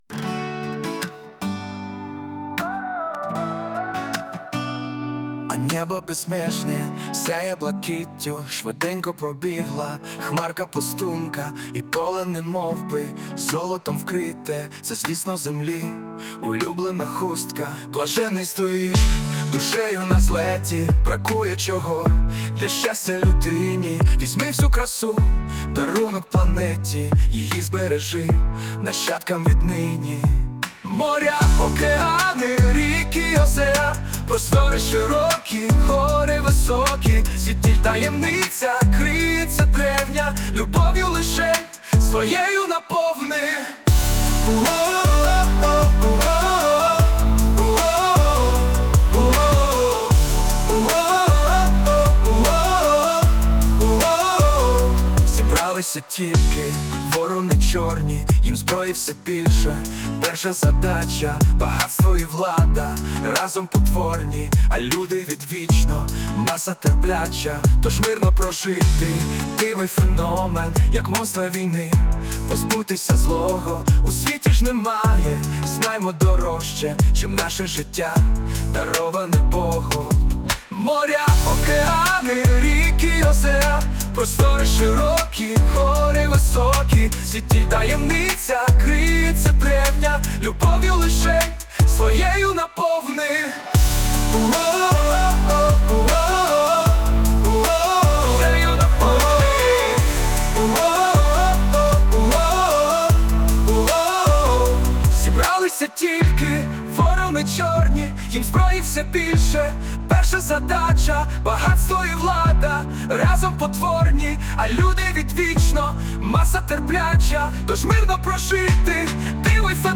Музична композиція створена за допомогою SUNO AI